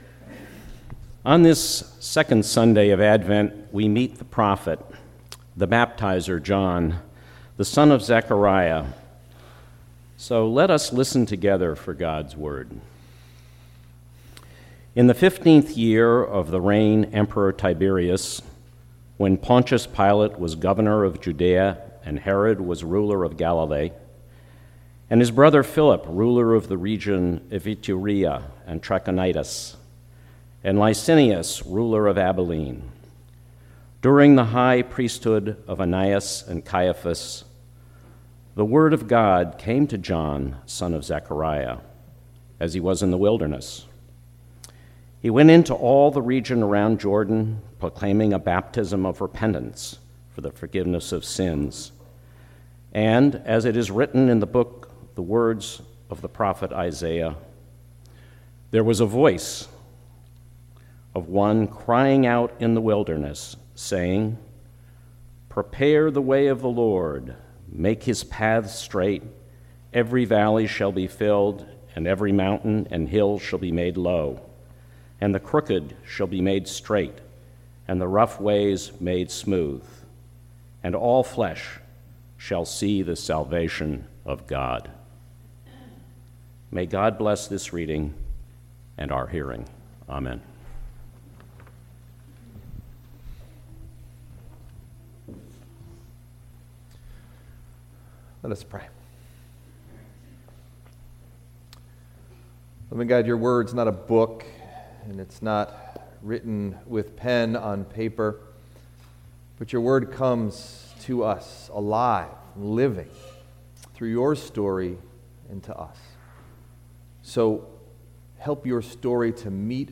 Message Delivered at: Charlotte Congregational Church (UCC)